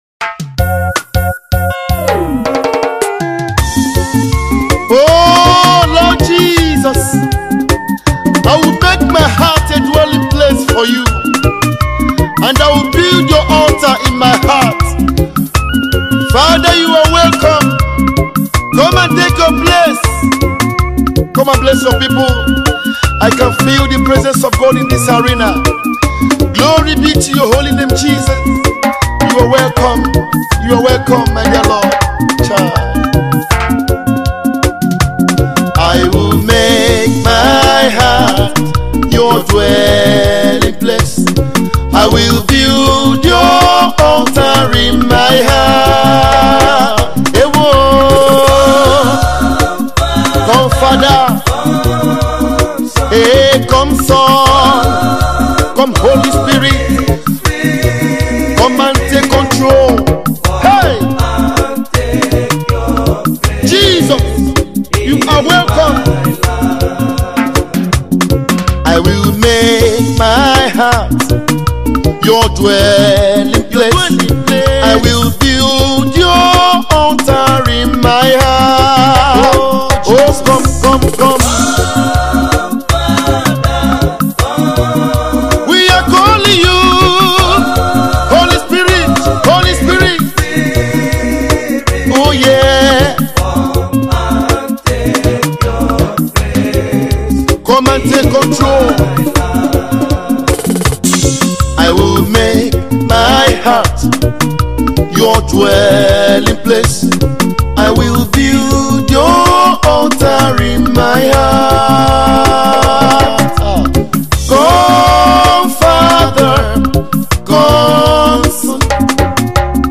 Igbo Gospel
worship single
Gospel